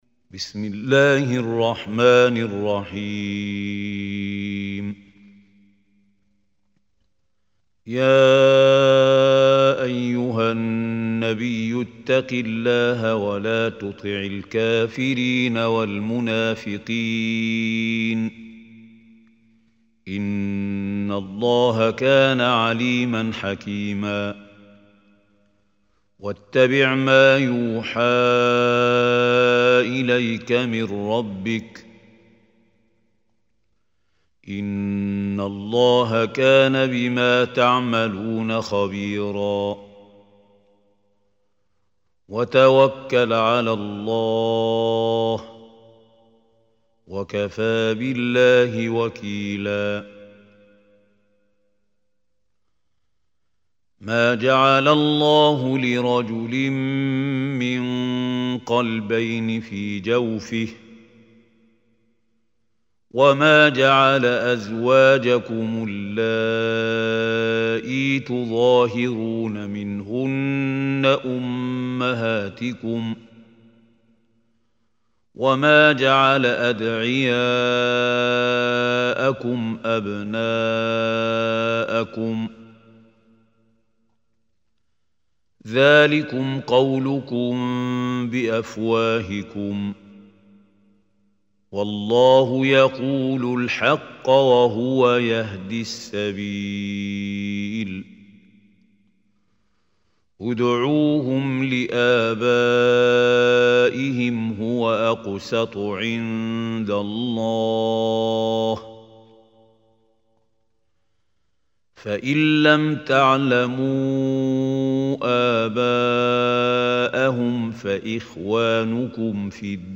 Surah Ahzab MP3 Recitation by Mahmoud Hussary
Surah Ahzab is 33 surah of Quran. Listen or play online mp3 tilawat/ recitation in Arabic in the beautiful voice of Mahmoud Khalil Al Hussary.